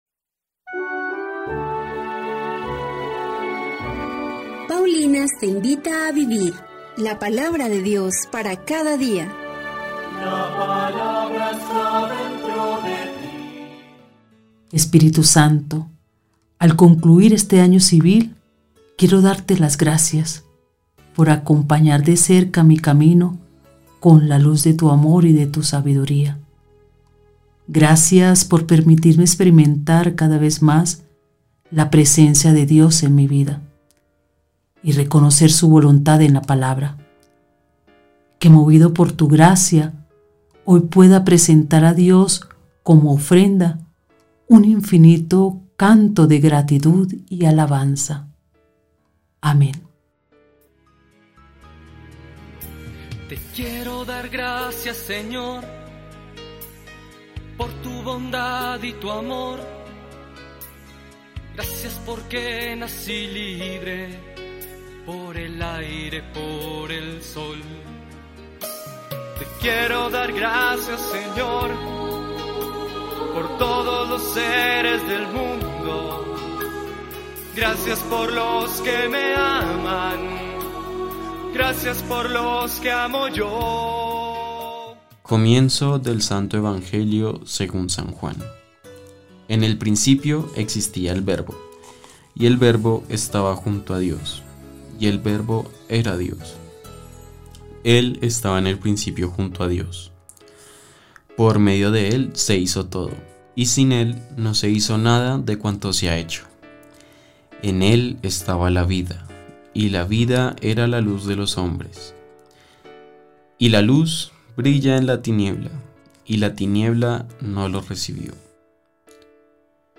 Lectura del libro del Eclesiástico 3, 2-6. 12-14